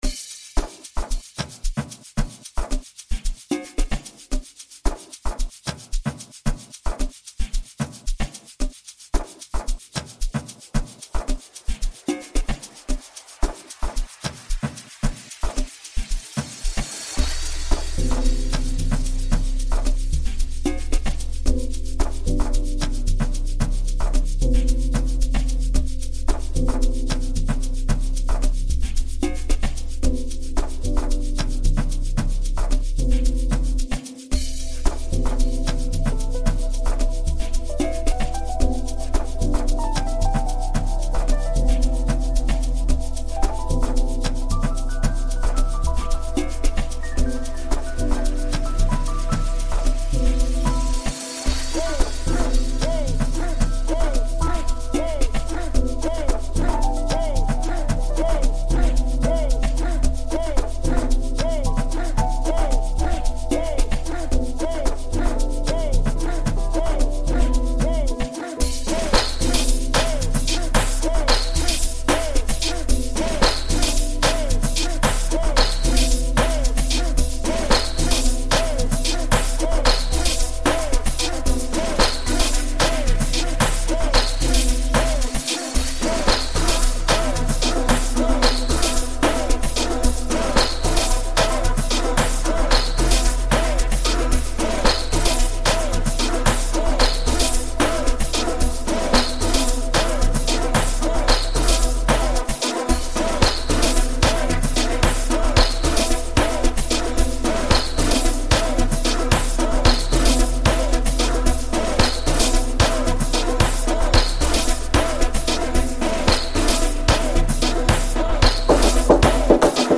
This invigorating track is an anthem of resilience